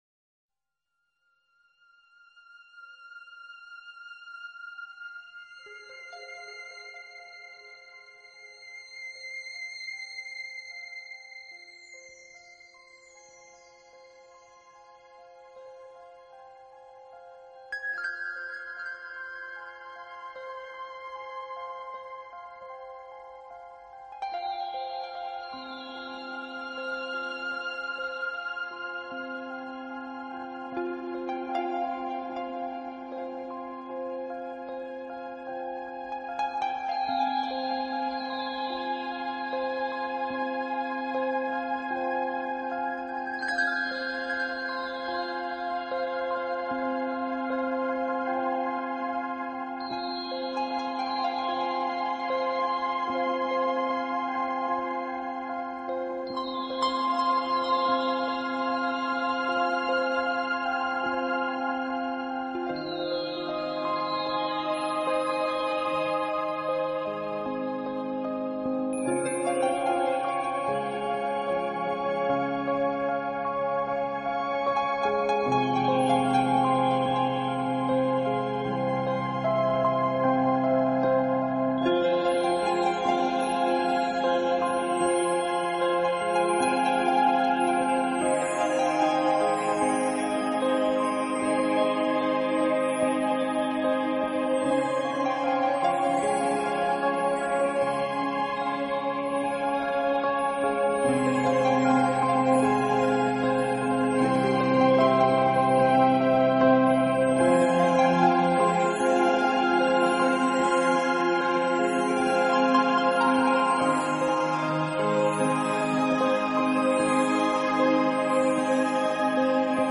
【新世纪纯音乐】
音乐风格： 新世纪/凯尔特|新世纪|(New Age)